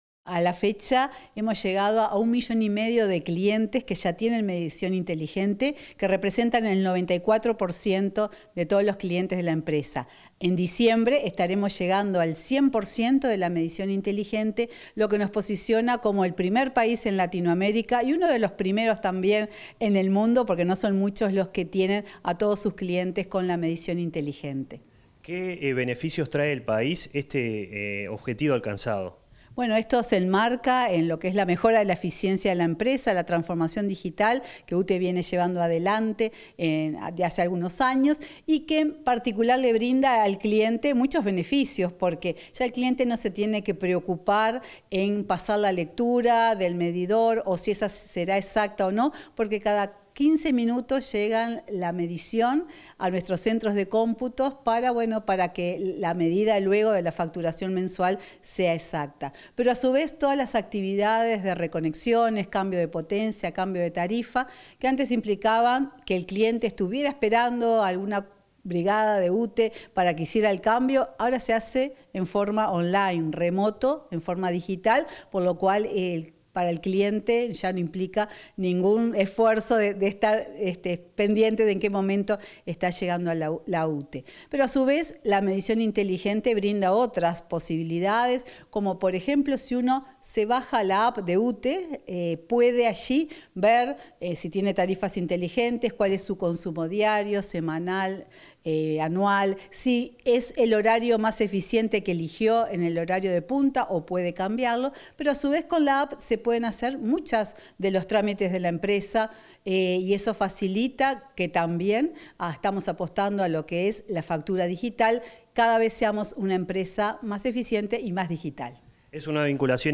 Entrevista a la presidenta de UTE, Silvia Emaldi